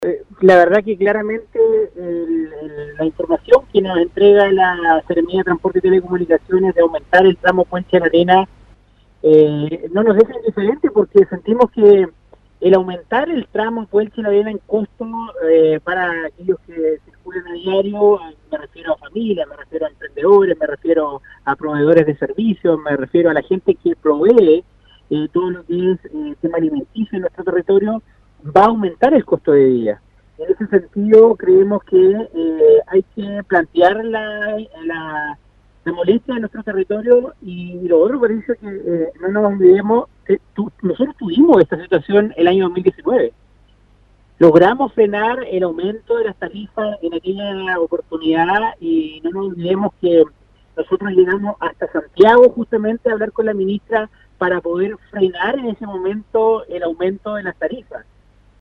Sobre esta situación se manifestó en contra el consejero de la provincia de Palena, Roberto Soto, quien dijo que se van a realizar gestiones para que este aumento en los precios no se concrete, ya que causaría un gran impacto en la zona.